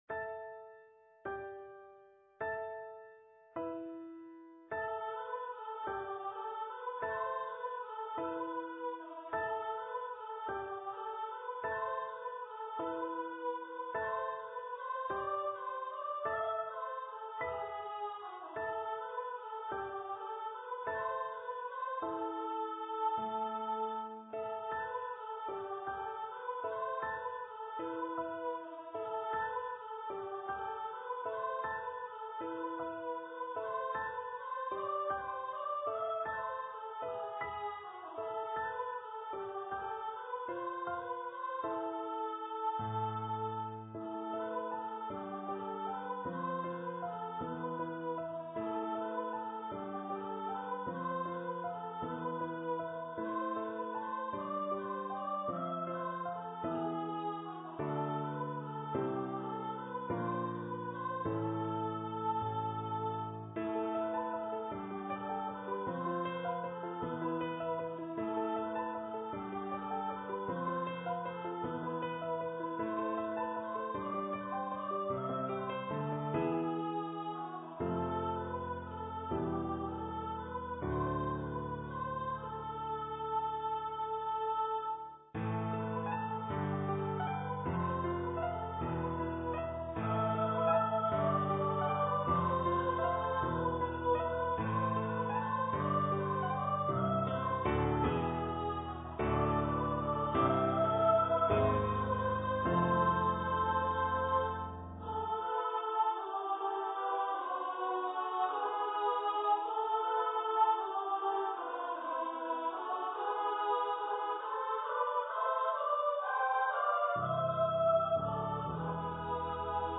for female voice choir